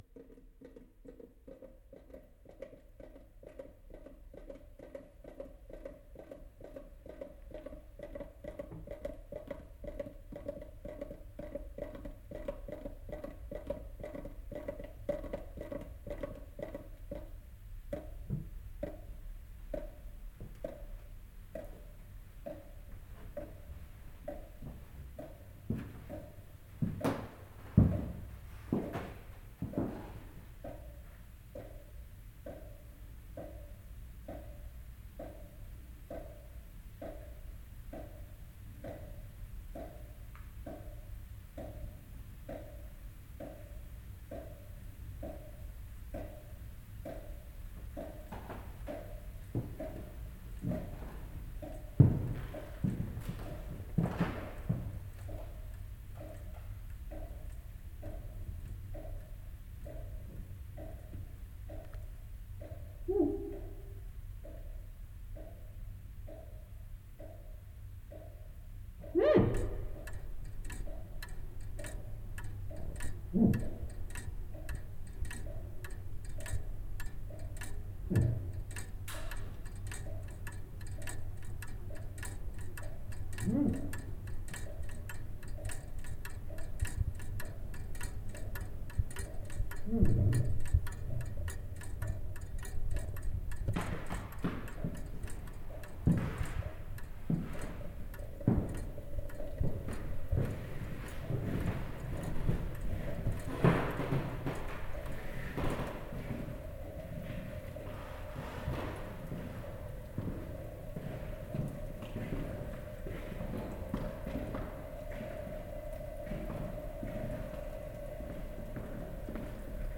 steps, water and clock | Zvuky Prahy / Sounds of Prague
field recordings, sound art, radio, sound walks
kroky, voda a hodiny
Tagy: interiéry lidé doma voda
zvuky kroků na skřípající podlaze, hry na okenní tabulky, pendlovky, kapajícího vodního kohoutku a vláčného pohybu v bytě v Malé Štěpánské ulici, Nové město. Nahráno pozdě v noci jako soundtrak k neexitujícímu trilleru.